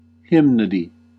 Ääntäminen
IPA : /ˈhɪm.nə.di/